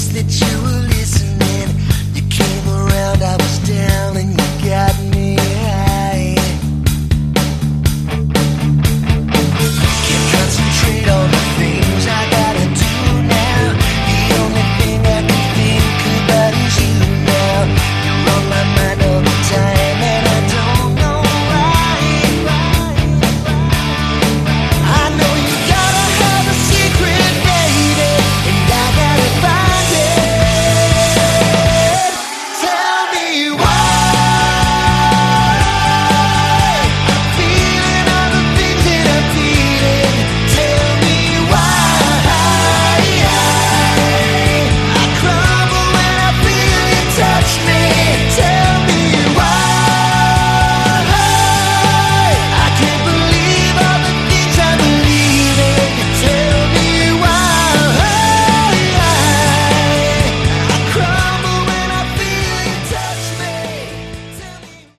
Category: Melodic Rock/Aor
lead vocals
guitars, bass, vocals
drums, keys, vocals